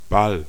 Ääntäminen
Synonyymit Redoute Tanzabend Abendunterhaltung (tanssi) Tanzball Gesellschaft Kugel Ballon Ääntäminen : IPA: /bal/ Haettu sana löytyi näillä lähdekielillä: saksa Käännös 1. baile {m} Artikkeli: der .